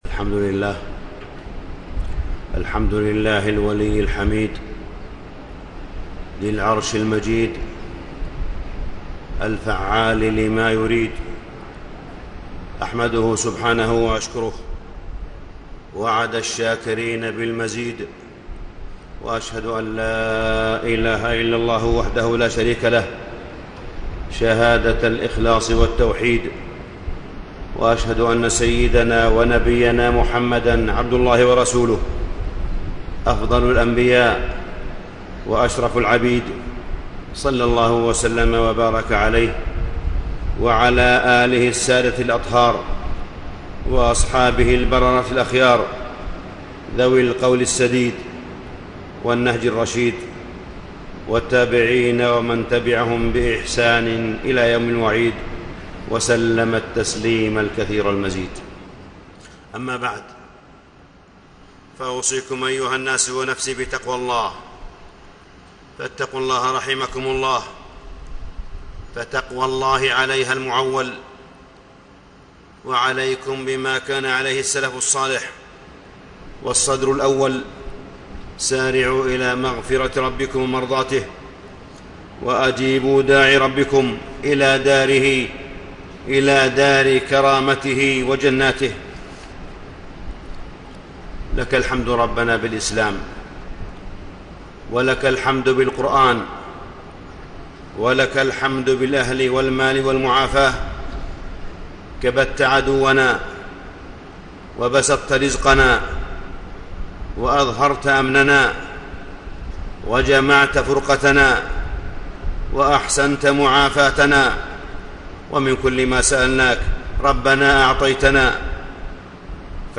تاريخ النشر ١٨ رمضان ١٤٣٤ هـ المكان: المسجد الحرام الشيخ: معالي الشيخ أ.د. صالح بن عبدالله بن حميد معالي الشيخ أ.د. صالح بن عبدالله بن حميد الشكر وفضل الشاكرين